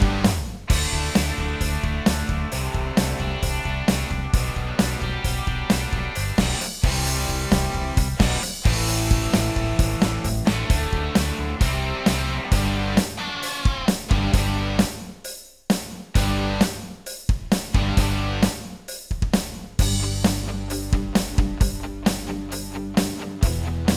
Minus All Guitars Rock 3:57 Buy £1.50